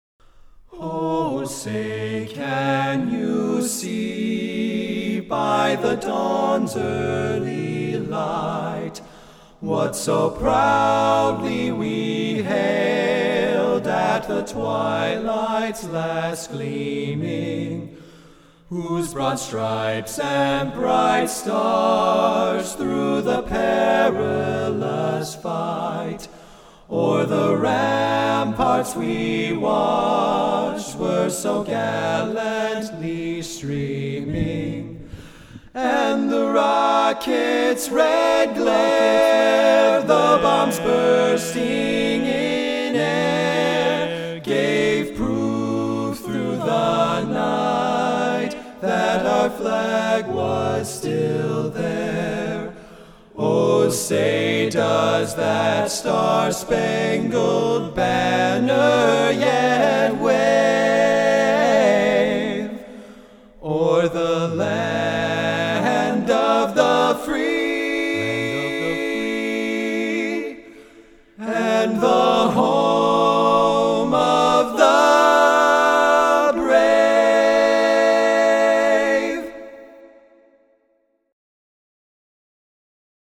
These two recordings are the only ones from the studio we've got so far.
Additional Live Recordings/Videos can be viewed on Youtube (provided below) by typing in our group name, Four Feet Long A Capella.